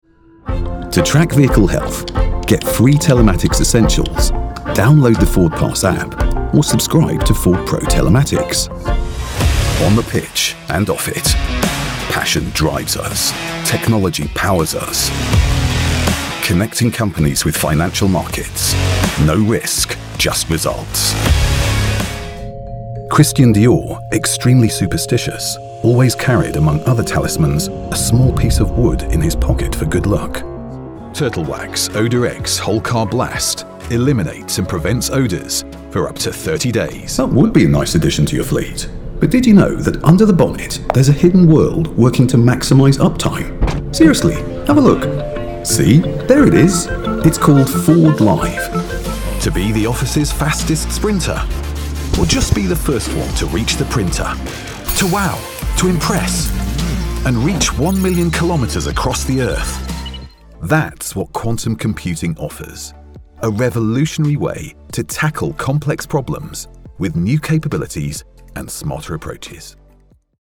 Inglés (Británico)
Comercial, Profundo, Natural, Llamativo, Seguro
Explicador